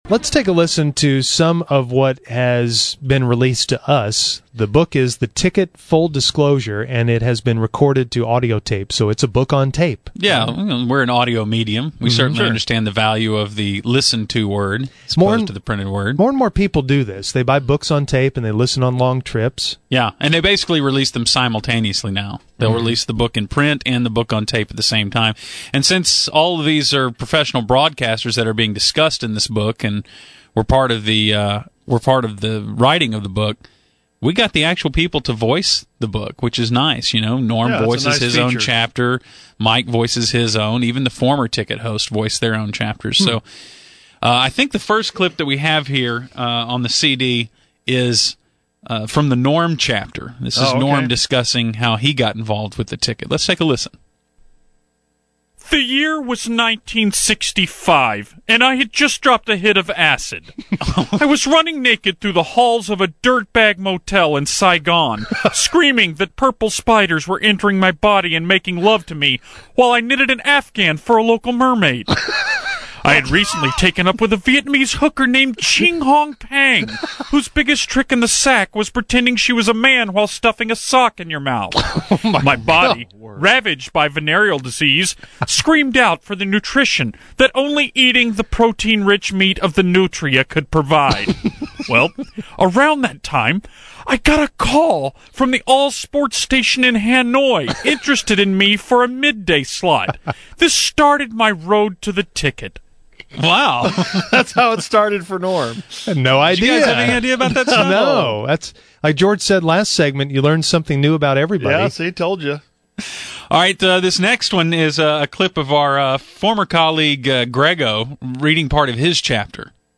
The Musers decided to give some insight to the new book The Ticket: Full Disclosure. They have some clips from the audio version of the book.